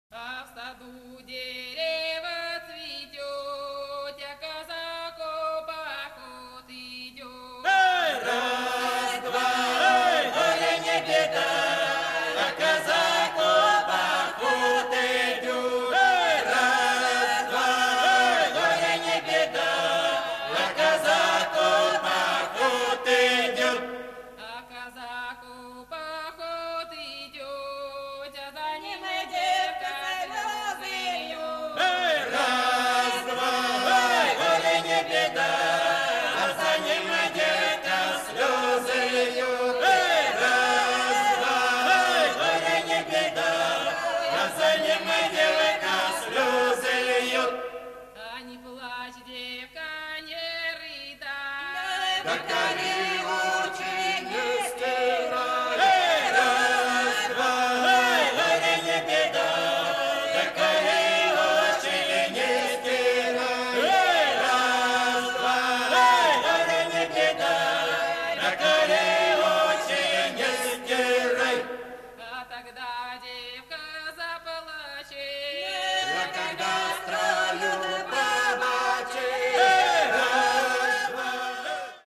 Kazak march song